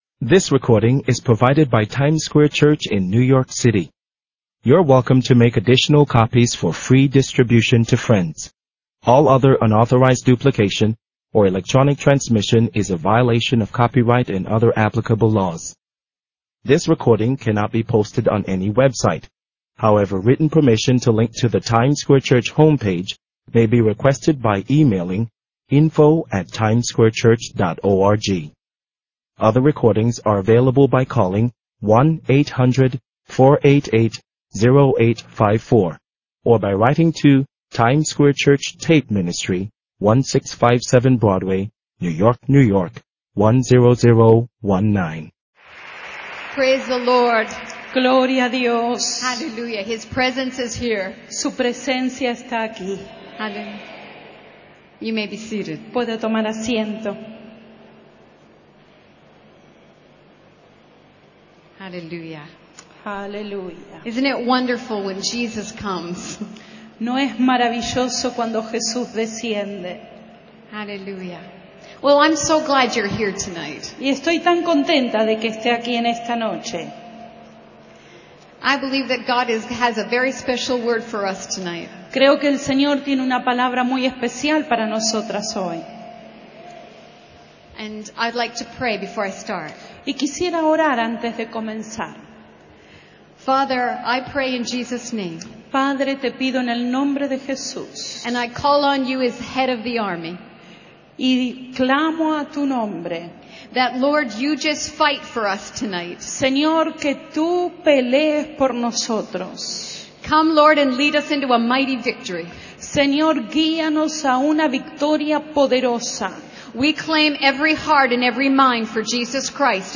You are welcome to make additional copies of these sermons for free distribution to friends.